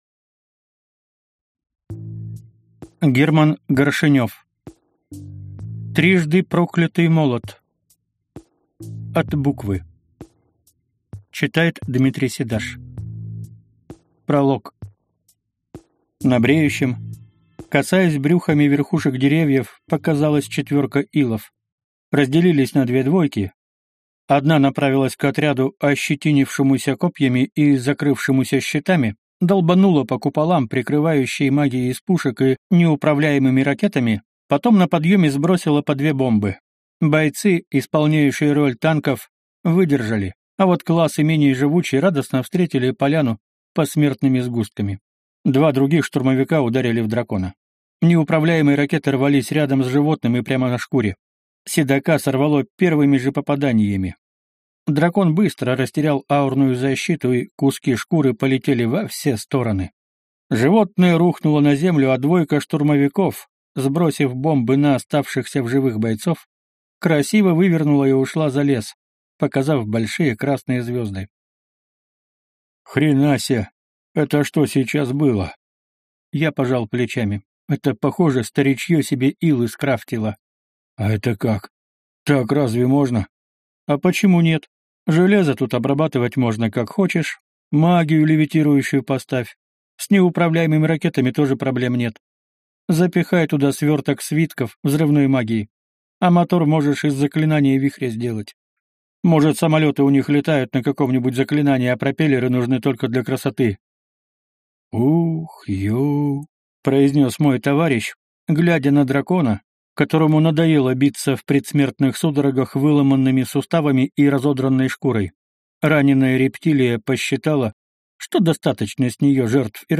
Аудиокнига Трижды проклятый молот. От Буквы | Библиотека аудиокниг